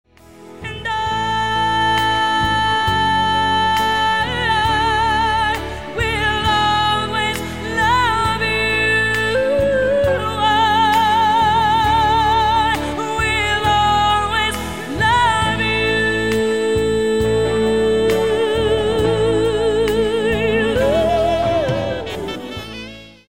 • Качество: 128, Stereo
красивые
красивый женский вокал